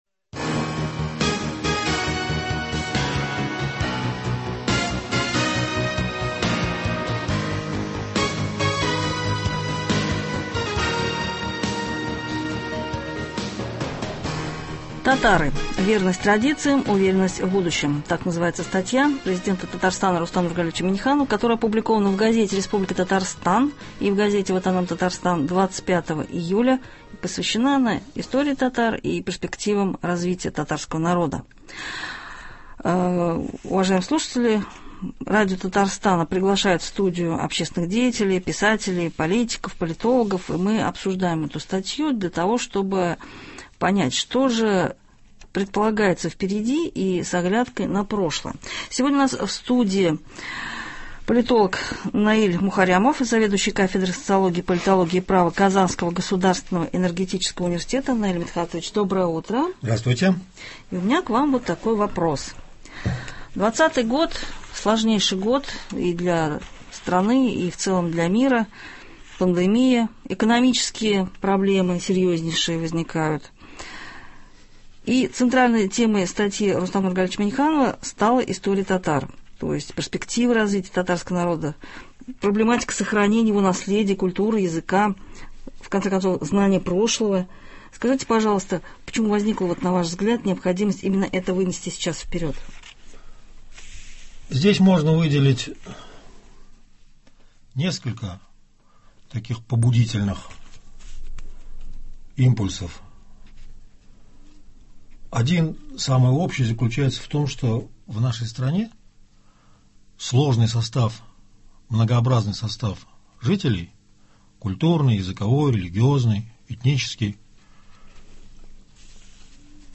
Радио Татарстана продолжает приглашать в студию политиков, политологов и общественных деятелей, которые комментируют положения статьи Президента